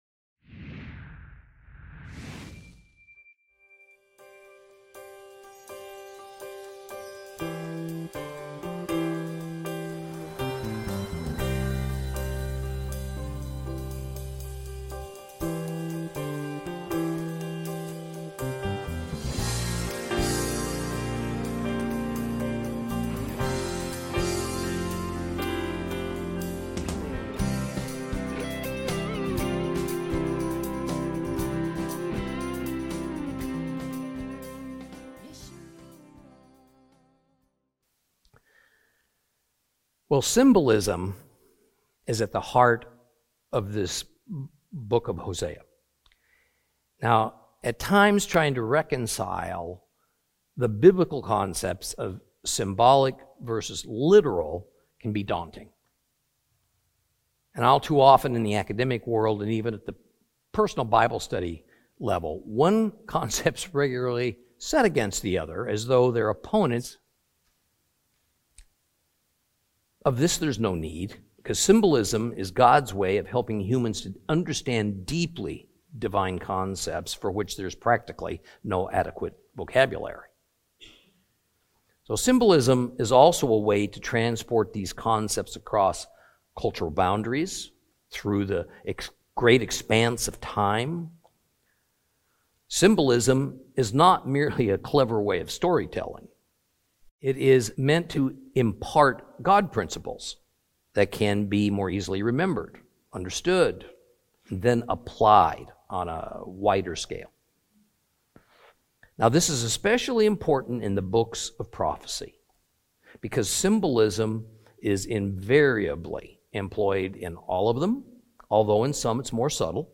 Teaching from the book of Hosea, Lesson 4 Chapters 1 and 2.